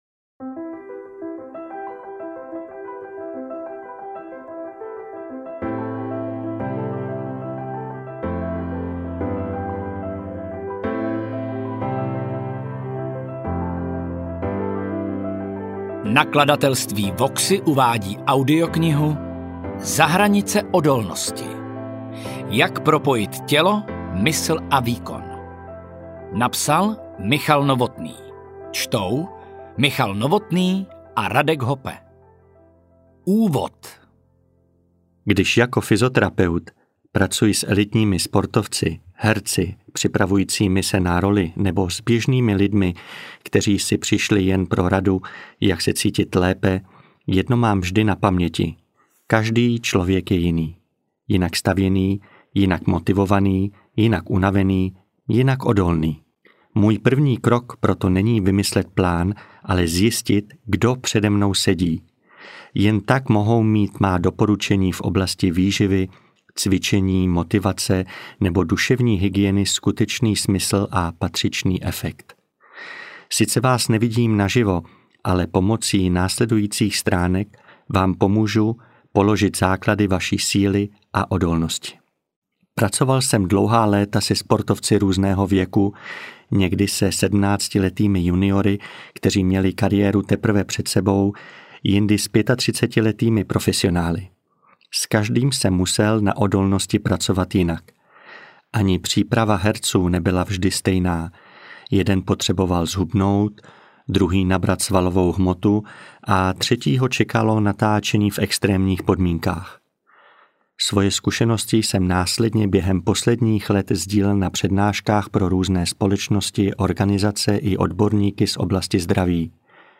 AudioKniha ke stažení, 21 x mp3, délka 7 hod. 6 min., velikost 387,9 MB, česky